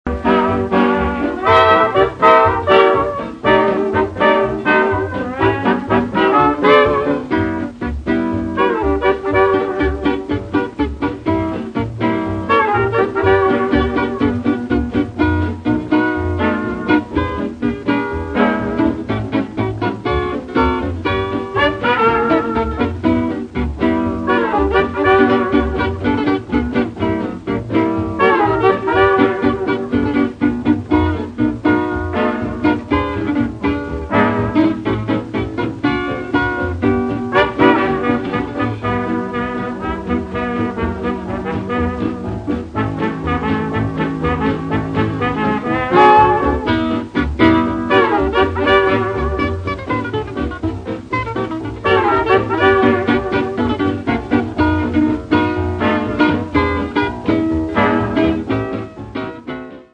voce
riversati su cd da 78 giri degli anni 40